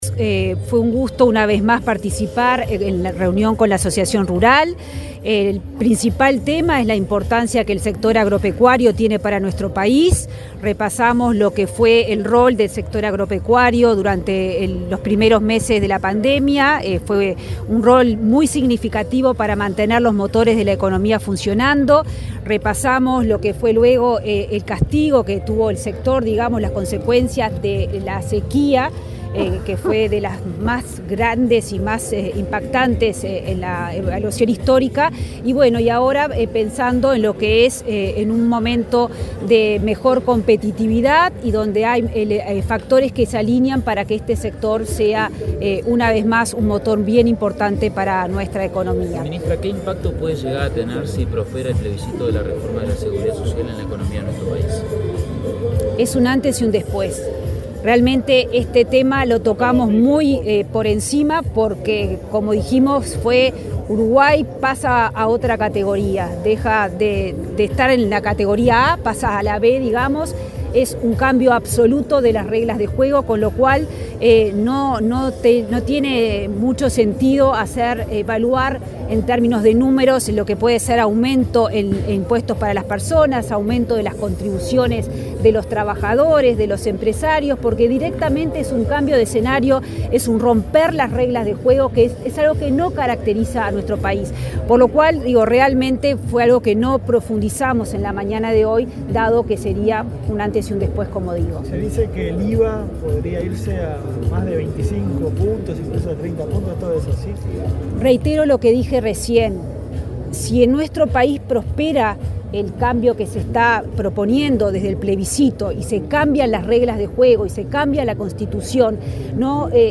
Declaraciones de la ministra de Economía, Azucena Arbeleche
La ministra de Economía, Azucena Arbeleche, dialogó con la prensa, luego de reunirse con directivos de la Asociación Rural del Uruguay en la Expo